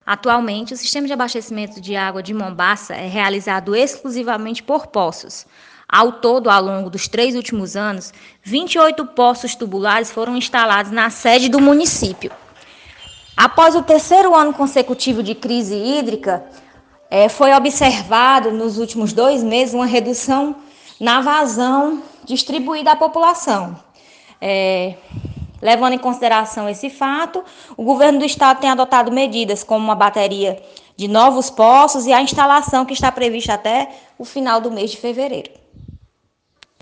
Entrevista sobre poços em Mombaça